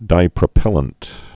(dīprə-pĕlənt)